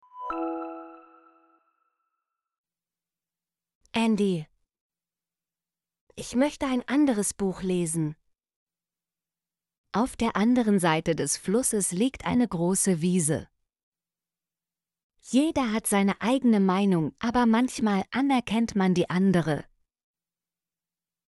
andere - Example Sentences & Pronunciation, German Frequency List